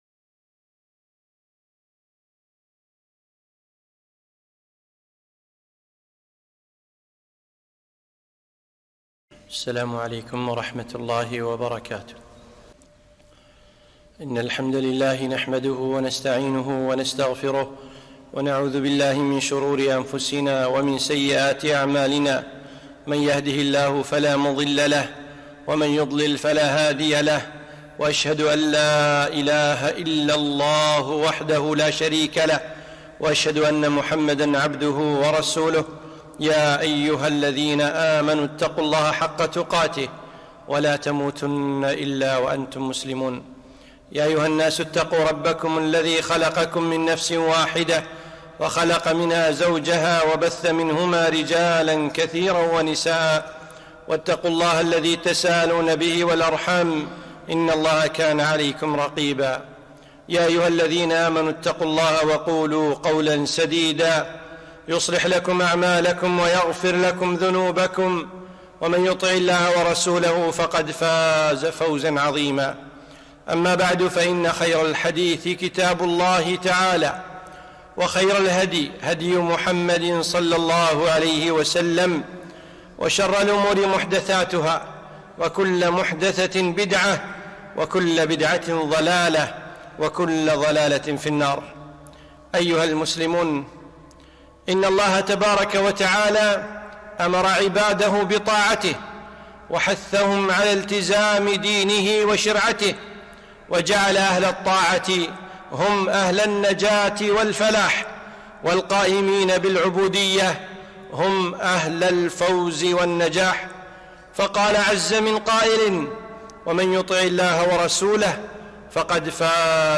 خطبة - المجاهرون